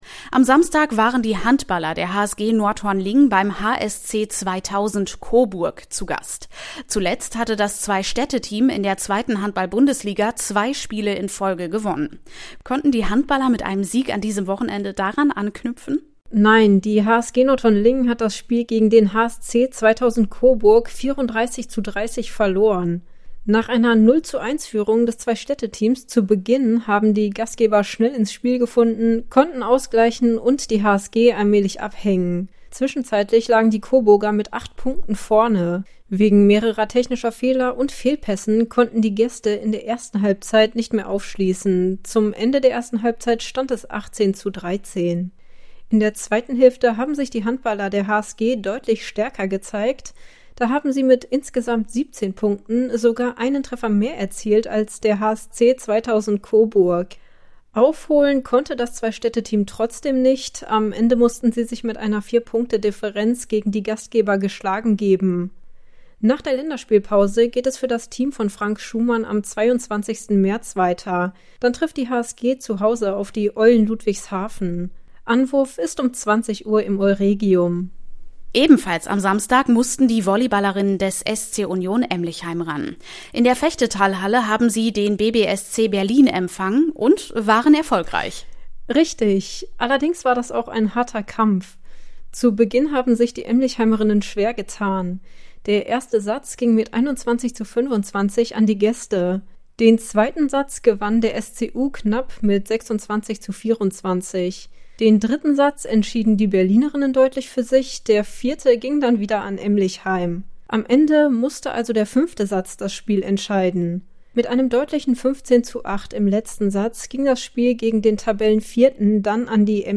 Sportrückblick: Frauenmannschaften feiern Erfolge